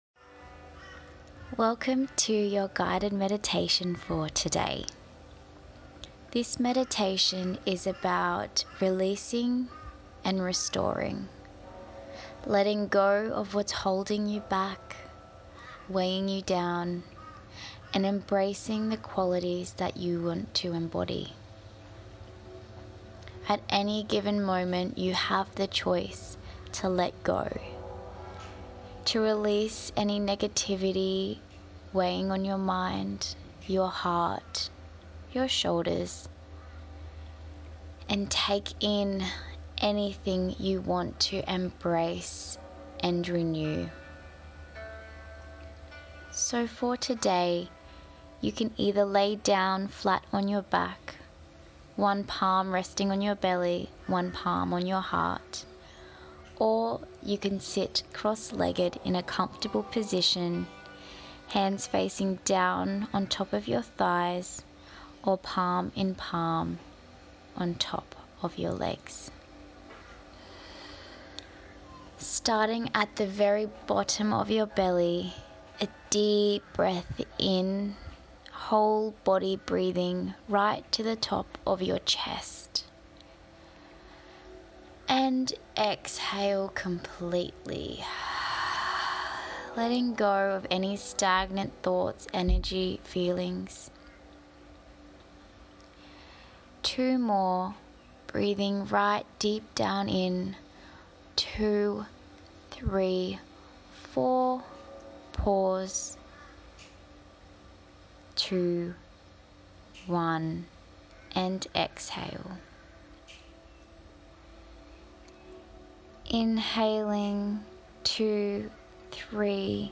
Guided meditations